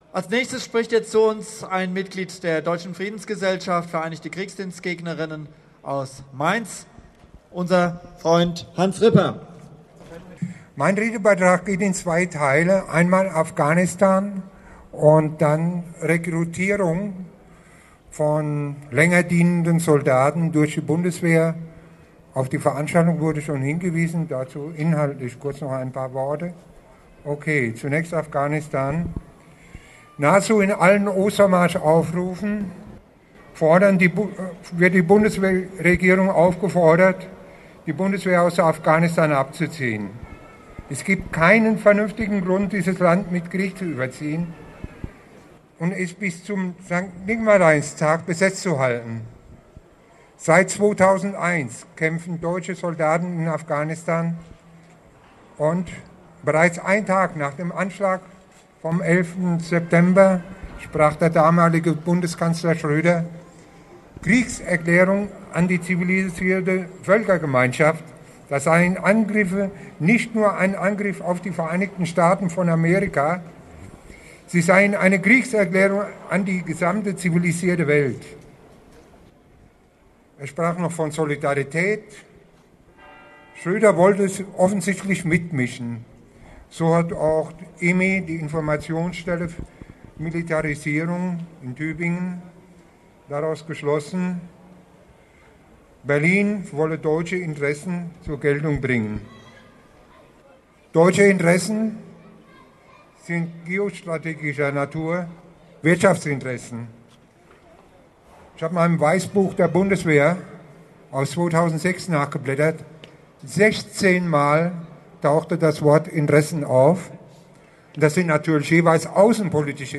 Redebeitrag